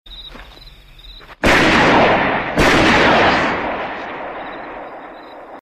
GUNSHOTS IN THE FIELD.mp3
Original creative-commons licensed sounds for DJ's and music producers, recorded with high quality studio microphones.
gunshots_in_the_field_vyh.ogg